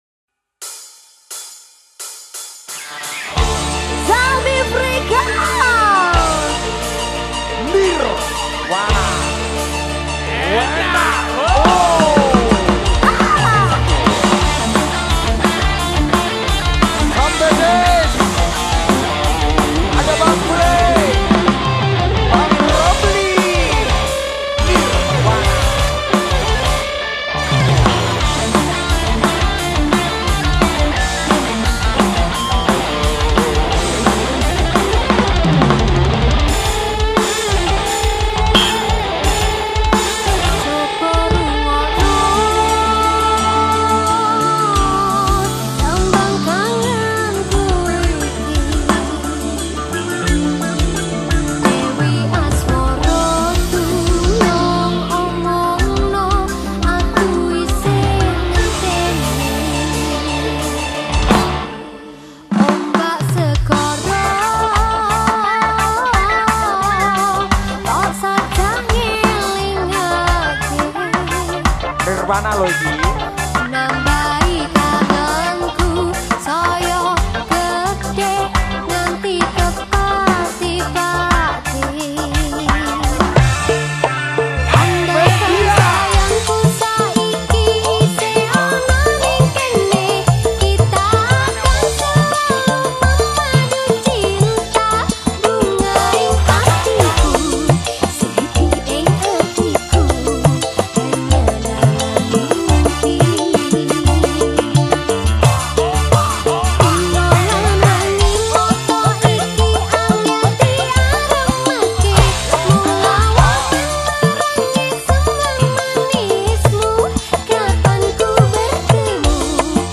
dangdut koplo